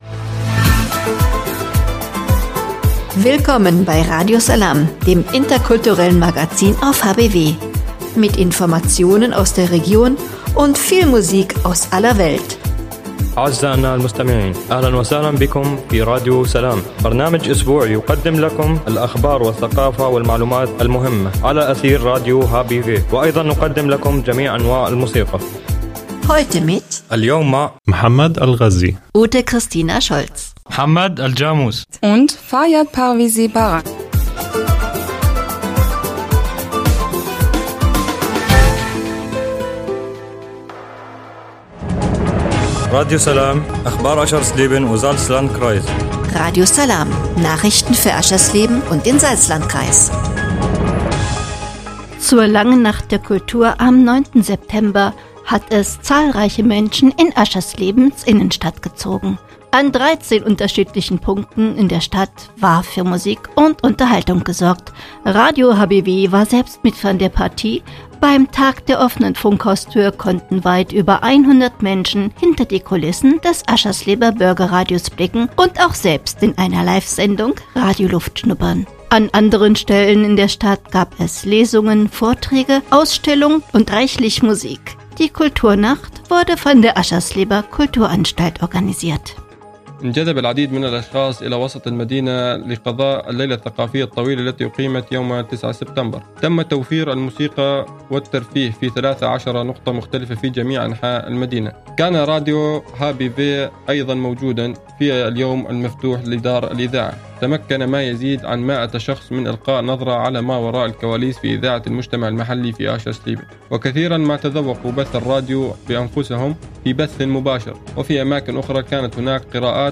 „Radio Salām“ heißt das interkulturelle Magazin auf radio hbw.
(Hinweis: Die in der Sendung enthaltene Musik wird hier in der Mediathek aus urheberrechtlichen Gründen weggelassen.)